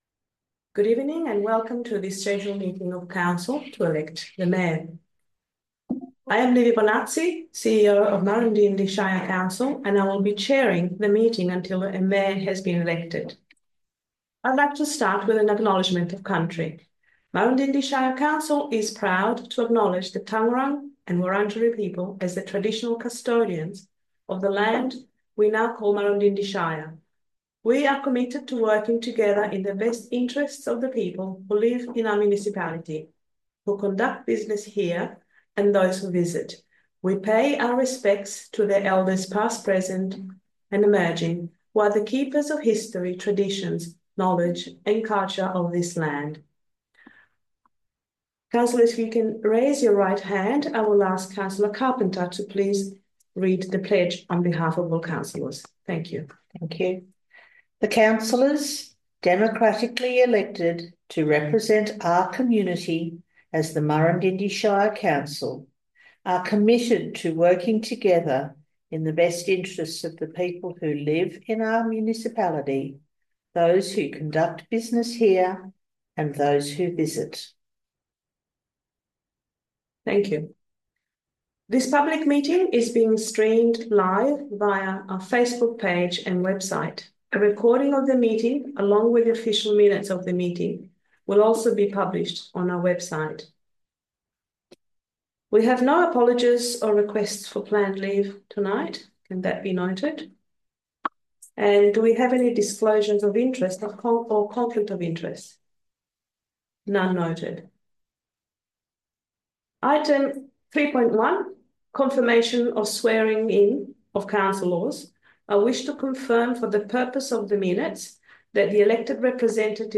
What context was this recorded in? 20 November 2024 Special Meeting of Council Alexandra Chambers, Alexandra View Map